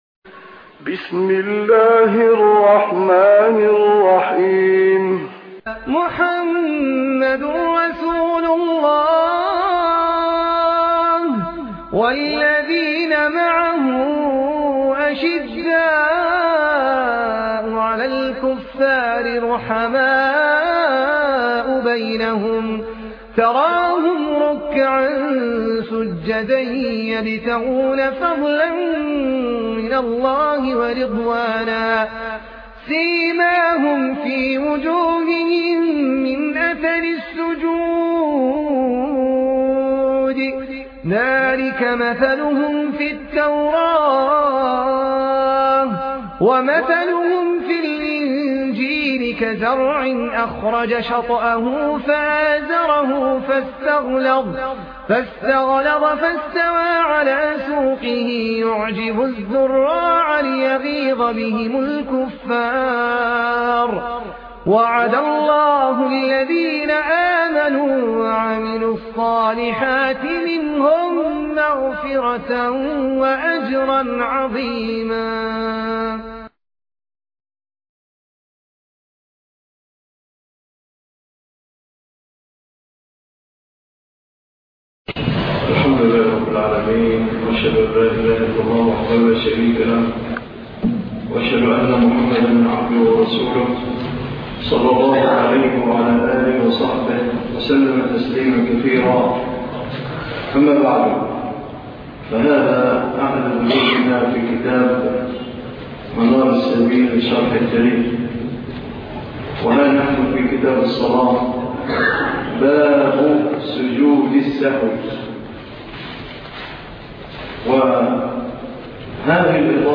الدرس 14 ( شرح منار السبيل